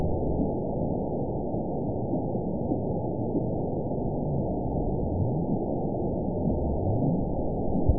event 917365 date 03/29/23 time 09:33:49 GMT (2 years, 1 month ago) score 7.76 location TSS-AB05 detected by nrw target species NRW annotations +NRW Spectrogram: Frequency (kHz) vs. Time (s) audio not available .wav